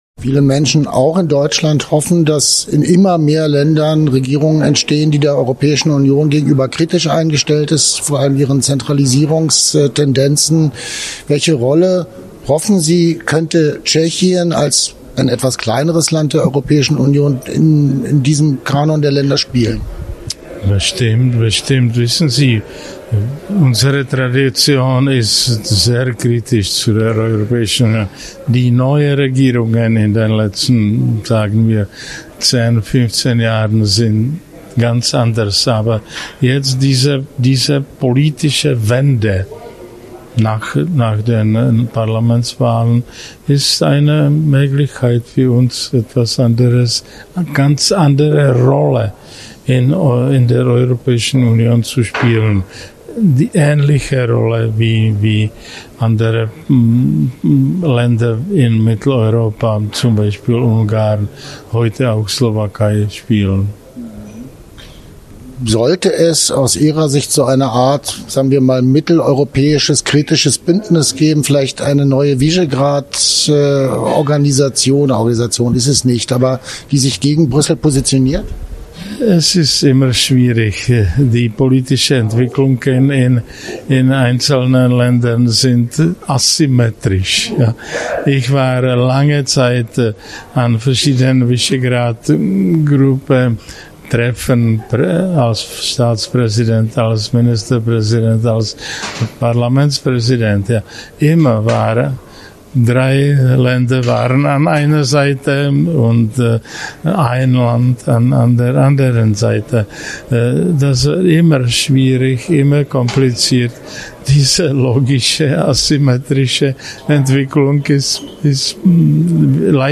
In Prag fand kürzlich das zweite Alternativ-WEF statt.